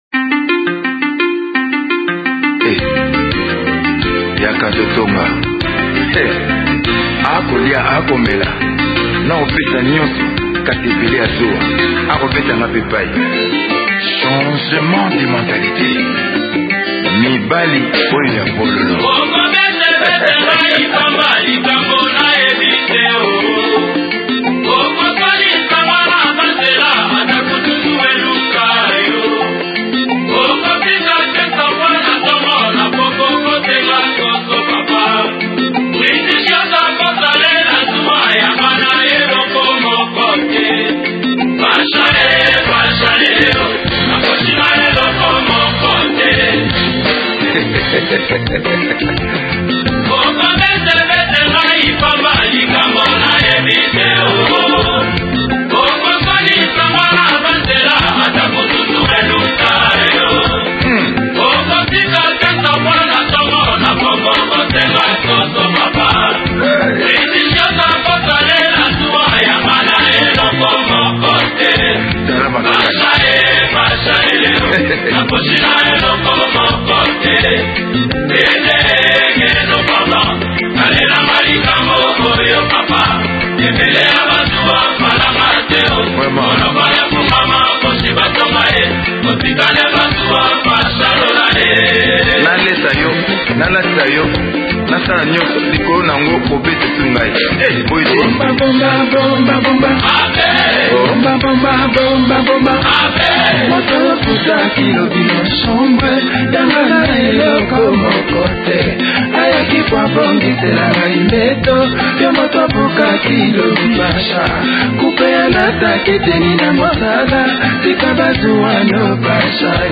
rumba congolaise